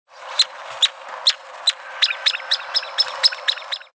Crotophaga sulcirostris (groove-billed ani)
Here is an example of a Groove-billed Ani (Crotophaga sulcirostris) recorded above Grano de Oro in the Talamancas (Costa Rica), 6/24/99, illustrating the call notes leading to the "song".